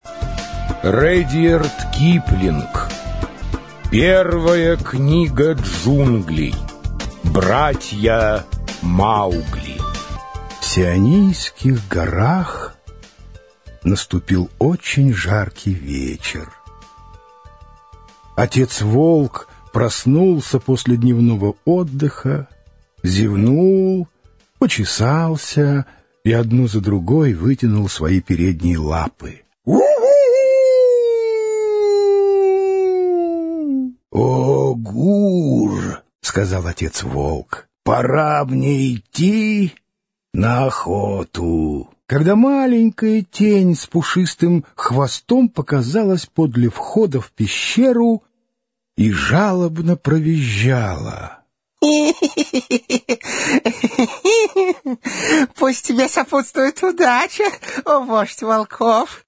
Аудиокнига Братья Маугли. Аудиоспектакль | Библиотека аудиокниг